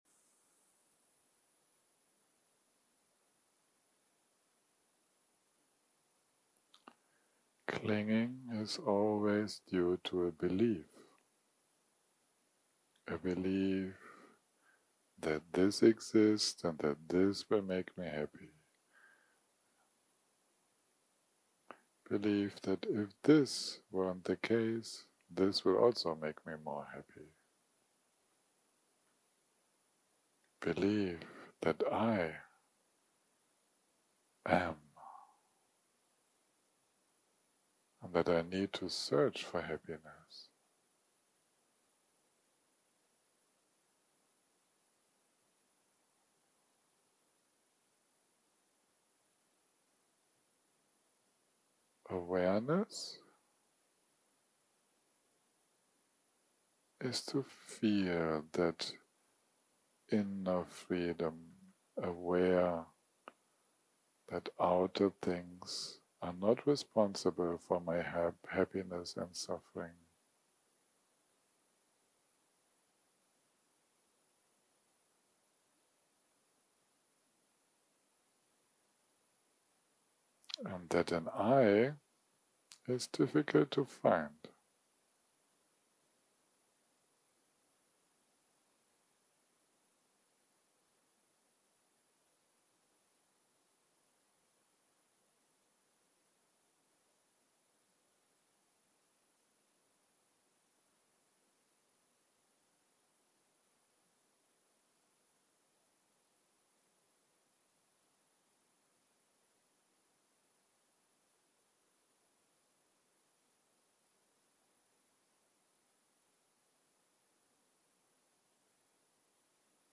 יום 6 - הקלטה 26 - בוהריים - מדיטציה מונחית - Looking into the mind 0:00 0:00 סוג ההקלטה: Dharma type: Guided meditation שפת ההקלטה: Dharma talk language: English